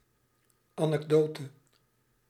Ääntäminen
Ääntäminen France: IPA: [yn‿anɛkdɔt] Tuntematon aksentti: IPA: /a.nɛk.dɔt/ Haettu sana löytyi näillä lähdekielillä: ranska Käännös Ääninäyte Substantiivit 1. anekdote Suku: f .